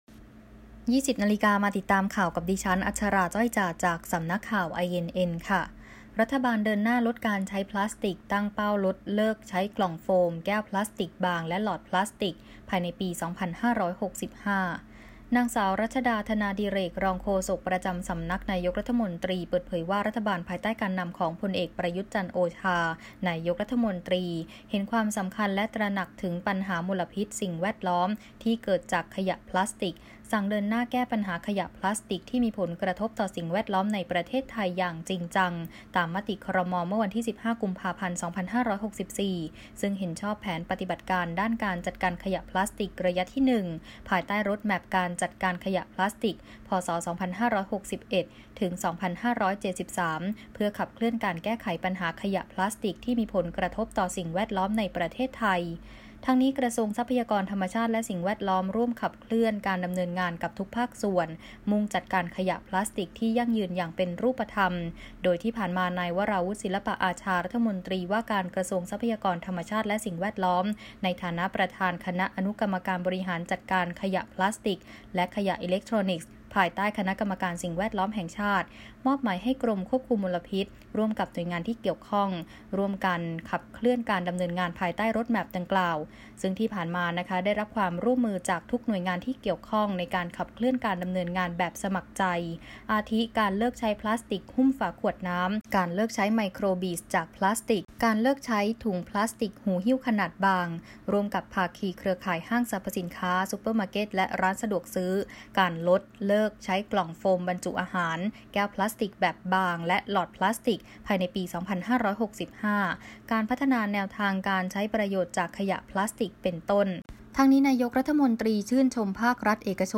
Video คลิปข่าวต้นชั่วโมง ข่าว